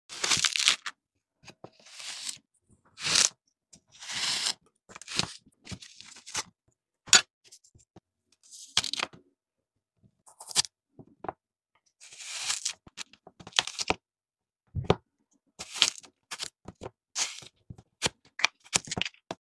kinetic sand shapes ASMR satisfaisant sound effects free download